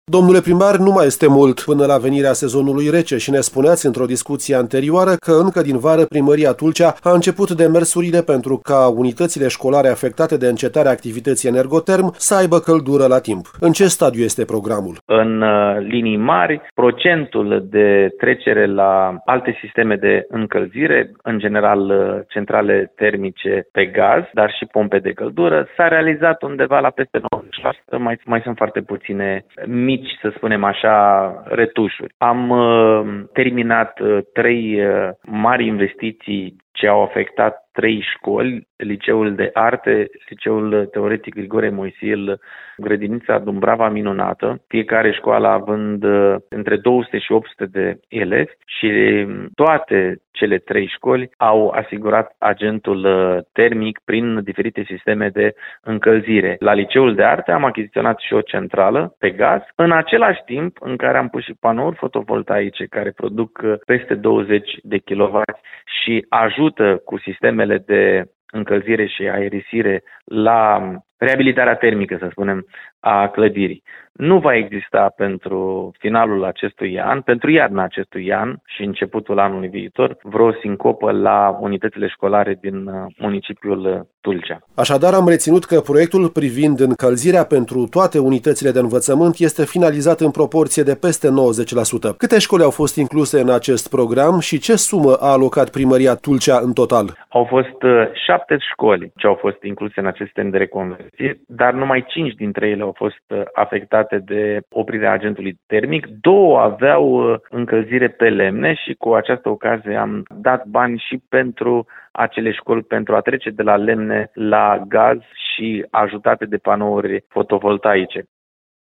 Trecerea la alte sisteme de încălzire este finalizată în proporție de peste 96% în școlile din municipiu, a declarat primarul Ștefan Ilie.